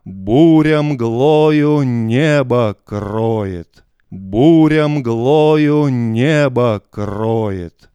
Слушаем один мик в правильной полярности и обратной. Микрофон сенх 845. Фраза -"Буря мглою небо кроет"- скопирована следом с небольшой паузой но уже с инверсией.
Вложения полярность.wav полярность.wav 684,7 KB · Просмотры: 308